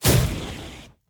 Firebuff 2.wav